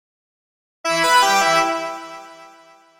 描述：Short noise designed for game responsiveness, lower pitch from SwitchA.
标签： short noise synth